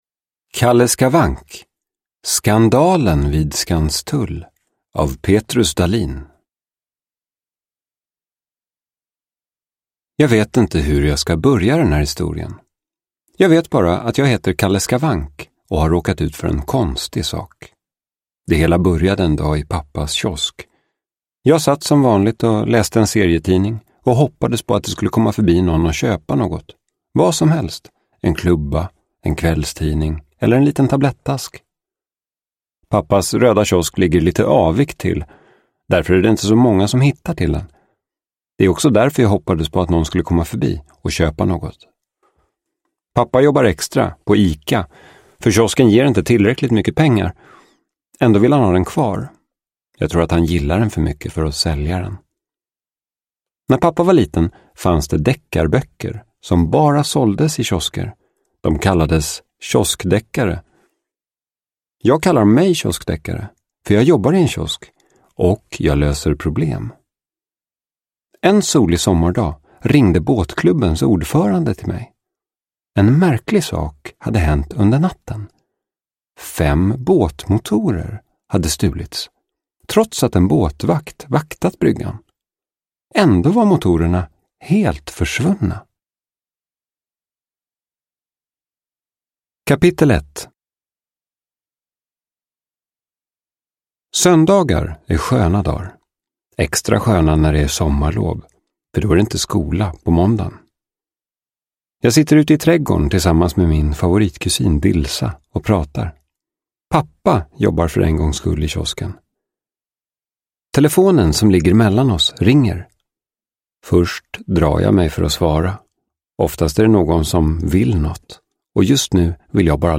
Skandalen vid Skanstull – Ljudbok